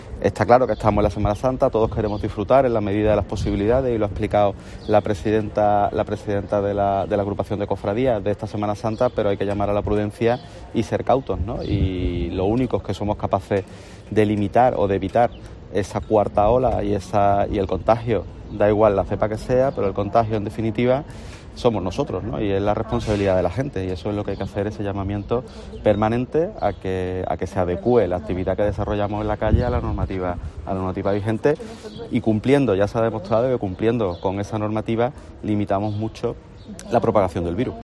En este sentido y en declaraciones a los periodistas, Repullo ha pedido a los cordobeses, "a título individual, y como colectivo", que "sean prudentes", pues "está claro que estamos en Semana Santa y todos queremos disfrutar", pero hay que hacerlo "en la medida de las posibilidades" que permite la pandemia.